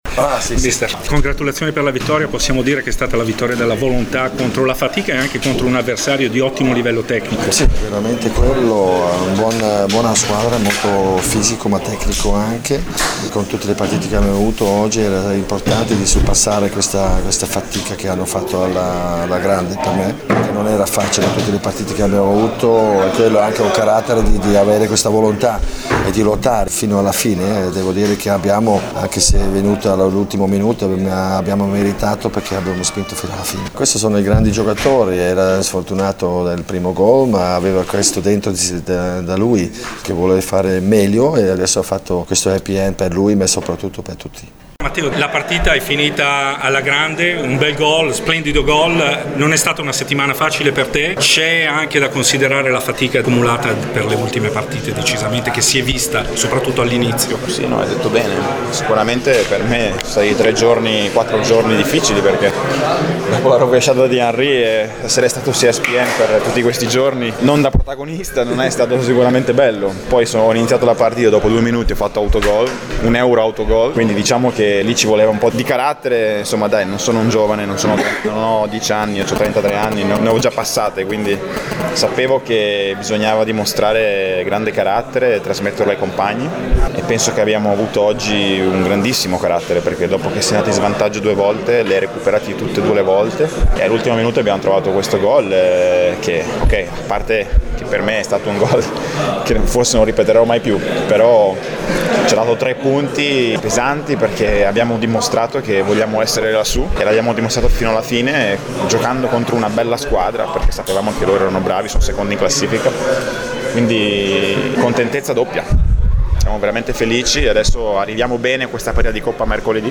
Servizio completo e interviste